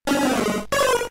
Cri de Piafabec K.O. dans Pokémon Diamant et Perle.